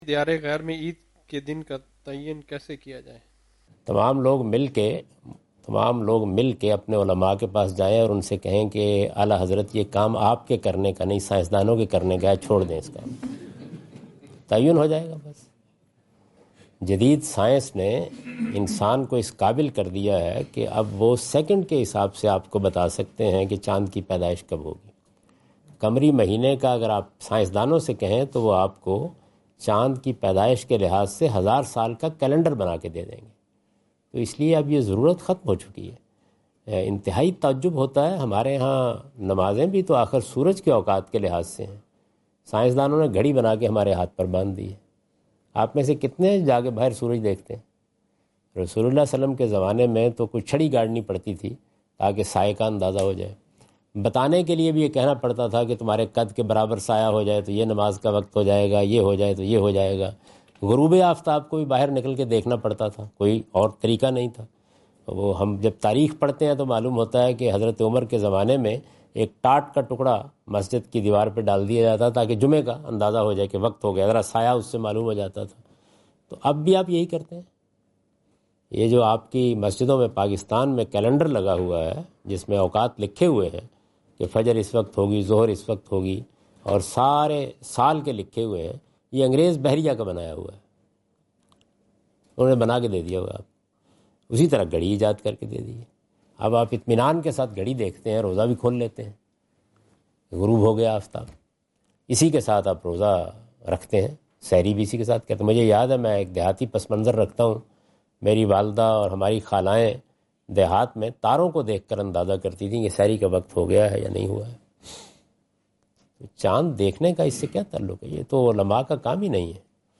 Javed Ahmad Ghamidi answer the question about "eid day in foreign countries" during his Australia visit on 11th October 2015.
جاوید احمد غامدی اپنے دورہ آسٹریلیا کے دوران ایڈیلیڈ میں "غیر مسلم ممالک میں یوم عید کا تعین" سے متعلق ایک سوال کا جواب دے رہے ہیں۔